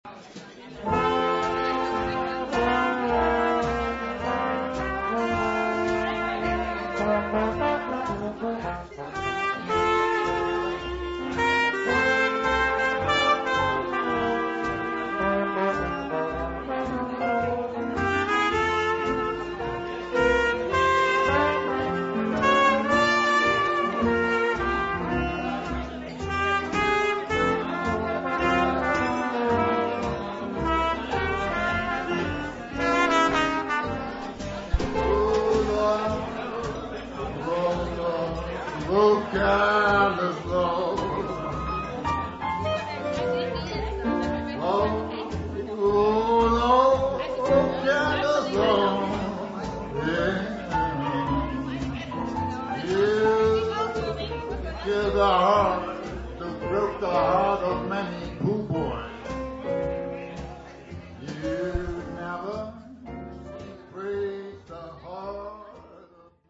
Live-Mitschnitt
Trompete
Klarinette
Klavier
Bass
Schlagzeug
Sextett
diese Band swingt!